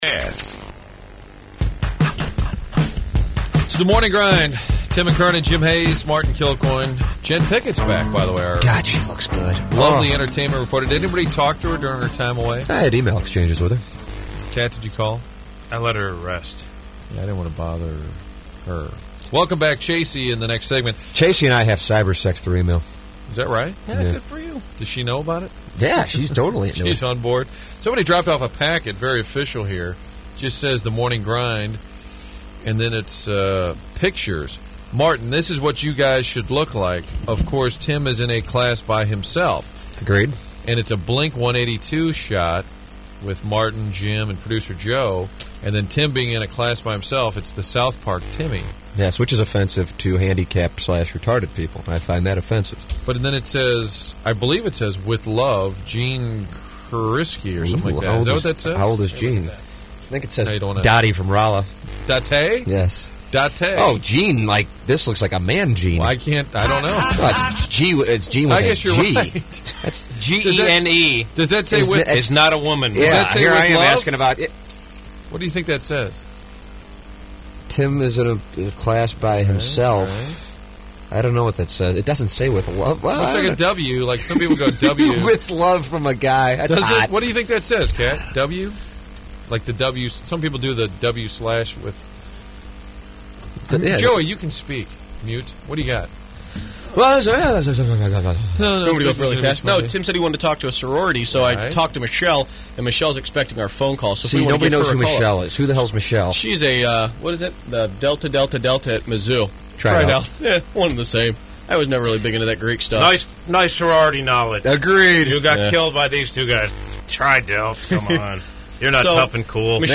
The Sorority Call-In
SororityPhoneIn.mp3